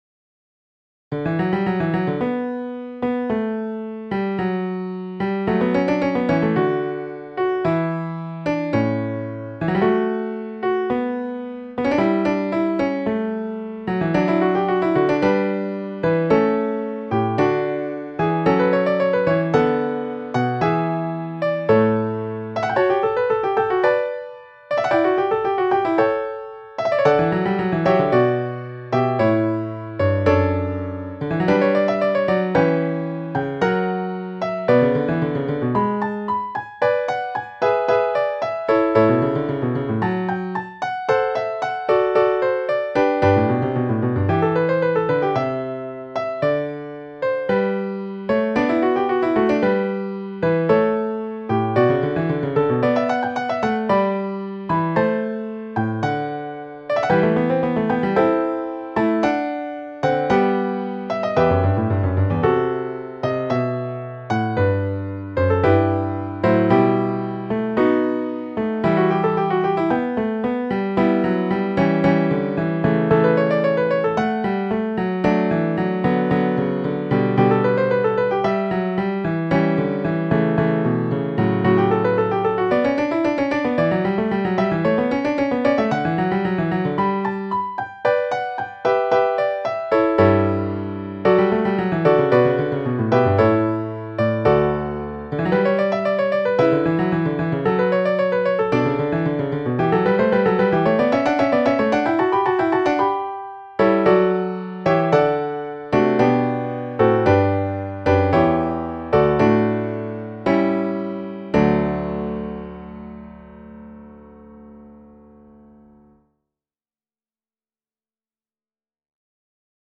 Fugue in D Major.mp3